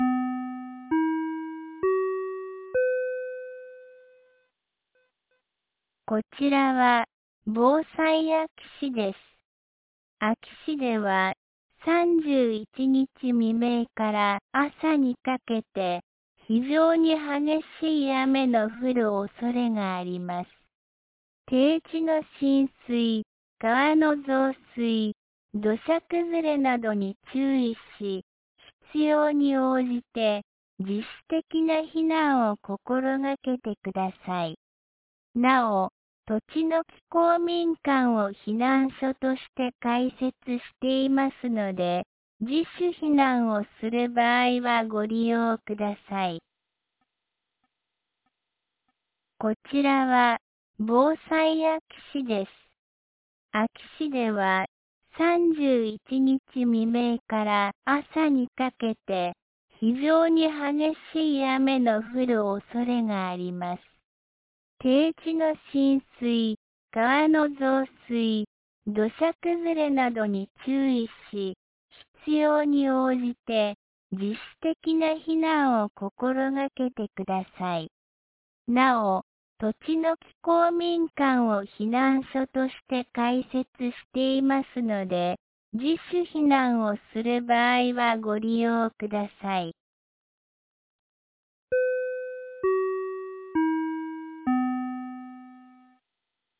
2026年03月30日 17時26分に、安芸市より栃ノ木へ放送がありました。